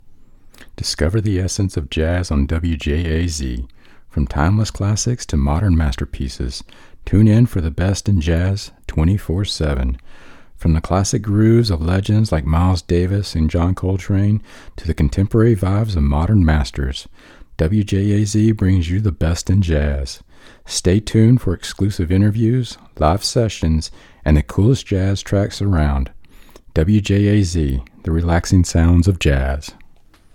English Speaking (US), neutral and southern dialects, young adult to mature senior voice
Sprechprobe: Werbung (Muttersprache):
Rode NT1 condenser mic Rode Pop screen Zoom H1 XLR Recorder Focusrite Scarlett 2i12 (3rd gen) interface Audacity DAW